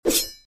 FirstComboAttackSfx.mp3